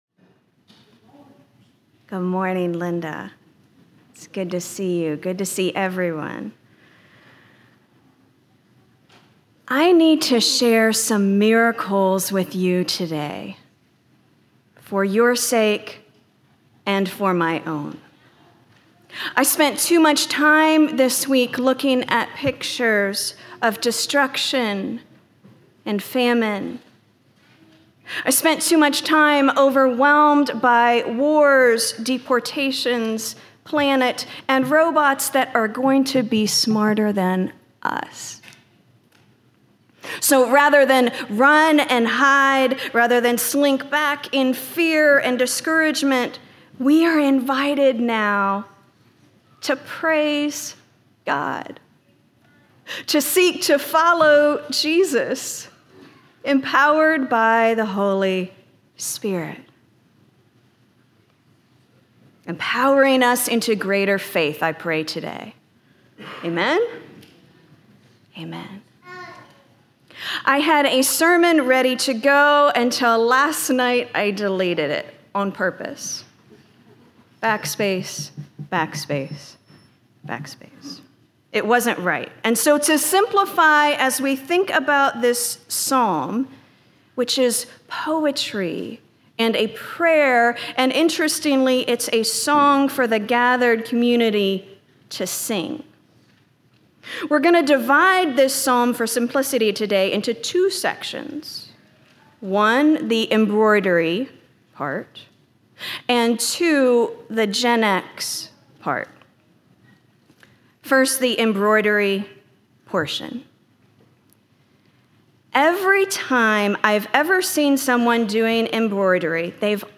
- Isaiah 55:1-13 Order of worship/bulletin Youtube video recording Sermon audio recording.